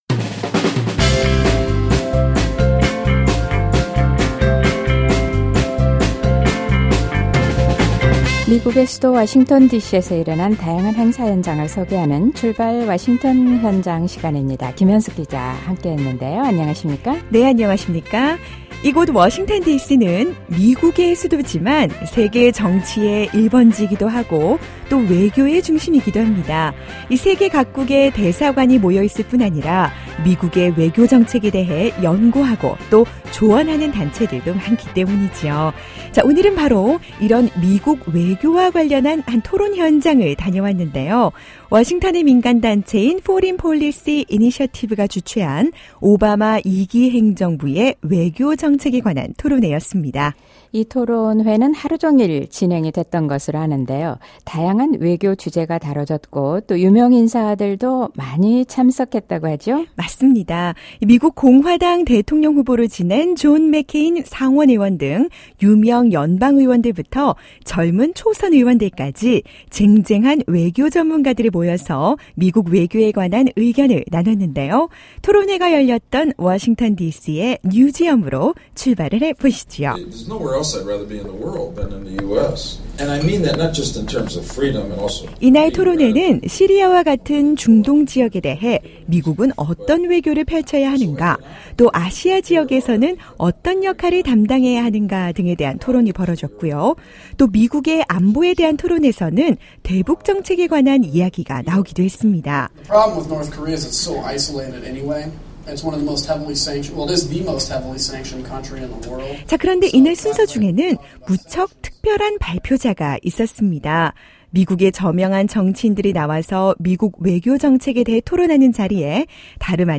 [출발! 워싱턴 현장] 탈북자 신동혁, 워싱턴 토론회 연설
미국의 인권외교가 어떤 방향으로 가야할지 삶으로 보여준 탈북자 신동혁씨의 연설현장을 찾아가 봤습니다.